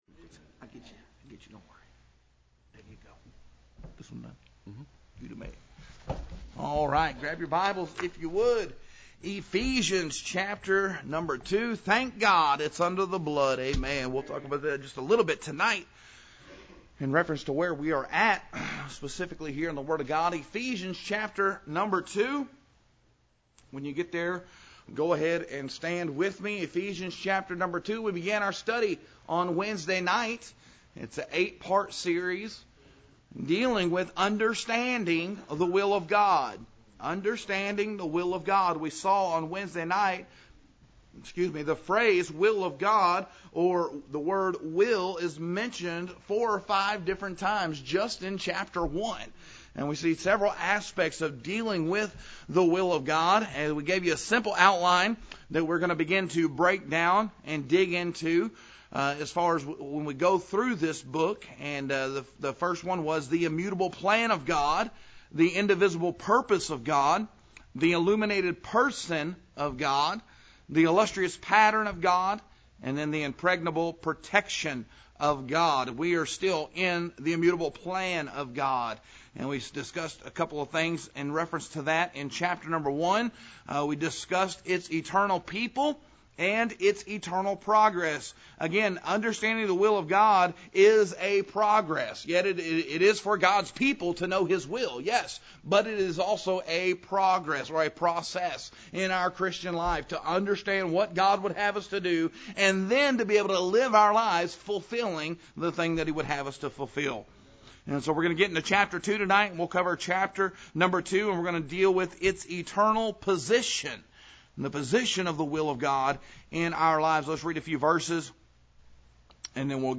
In Ephesians 2 the Spirit of God unfolds four complementary perspectives that explain where God has placed believers: our past state of guilt, our present state of grace, our permanent state of glory, and the perplexed state of the lost. This sermon explains these truths plainly so young adults and all church members can see both deep doctrine and practical application.